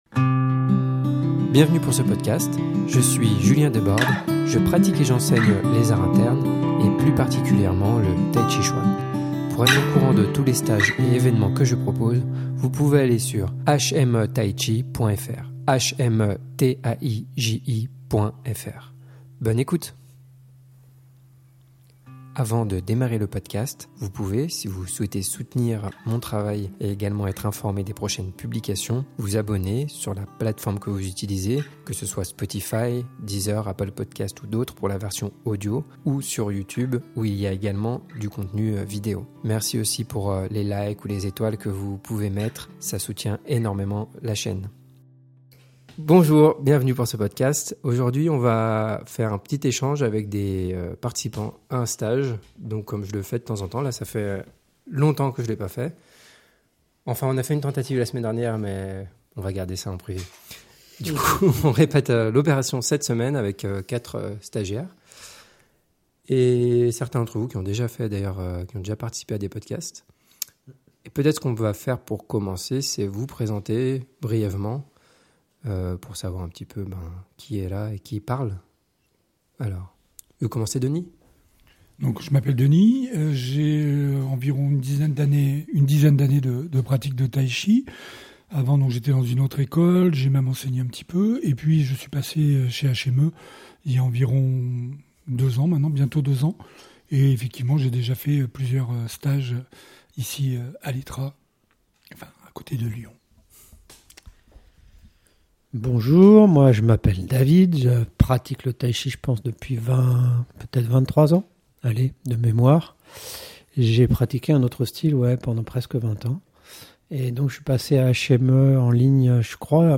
Discussion autour de la pratique du tai chi et des stage de tai chi